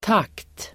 Uttal: [tak:t]